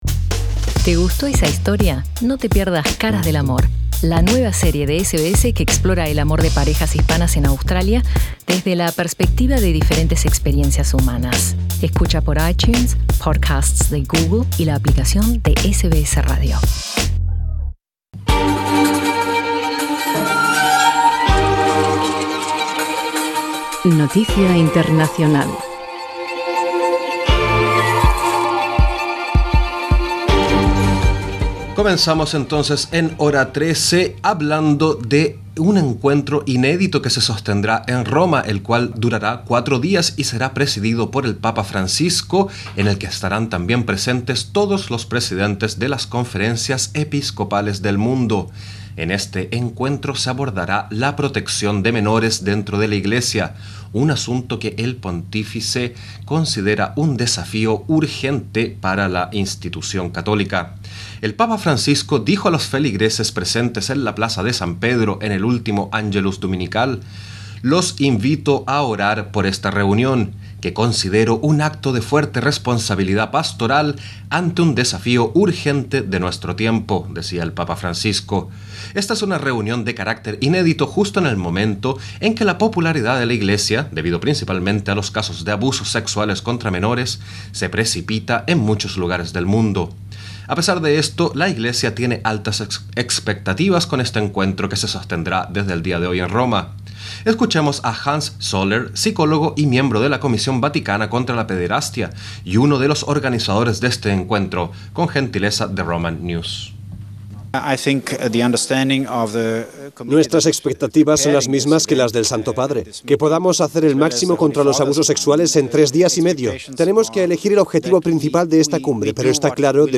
En Radio SBS te ofrecemos una entrevista